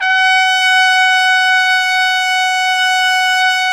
Index of /90_sSampleCDs/Roland L-CDX-03 Disk 2/BRS_Trumpet 1-4/BRS_Tp 3 Ambient